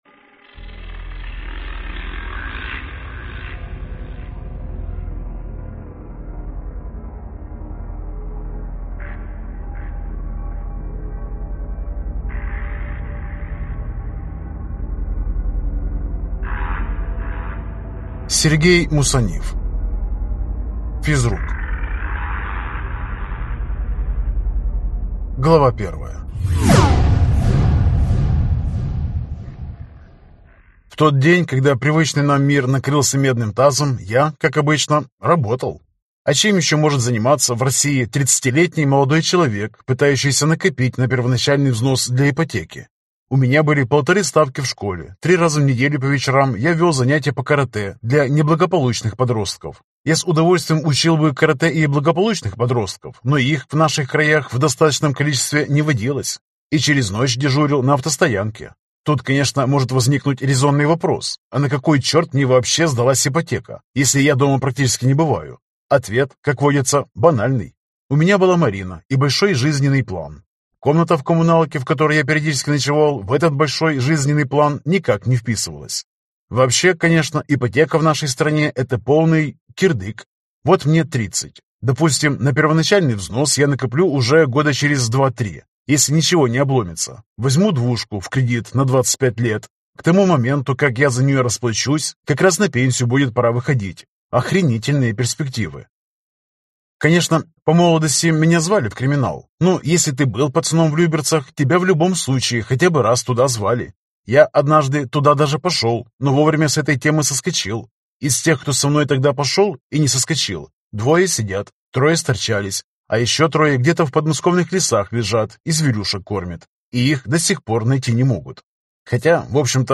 Аудиокнига Физрук. Книга 1 | Библиотека аудиокниг